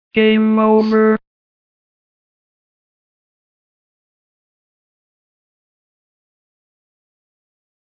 Robot Voice Video Game Sound FX
Video game sound effects. Robot voice says "Game over".
32kbps-triond-game-over.mp3